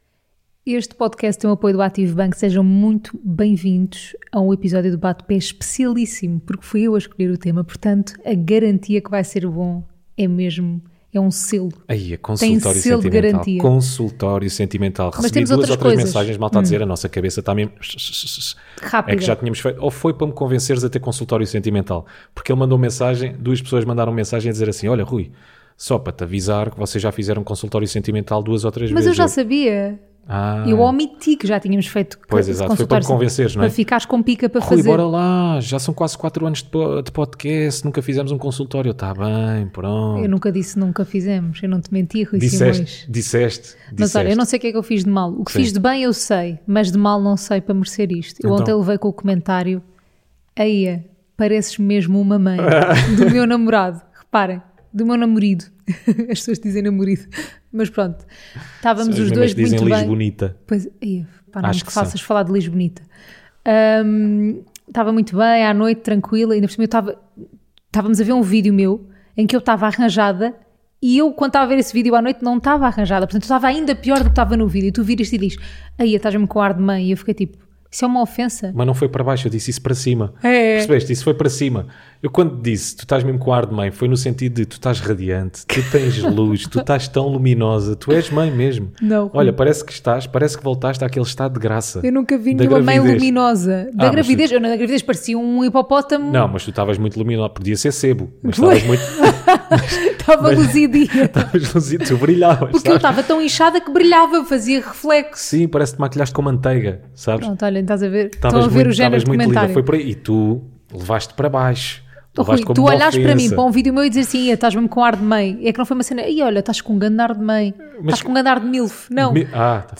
Um casal à conversa sobre temas pouco relevantes para uns e muito pouco relevantes para outros.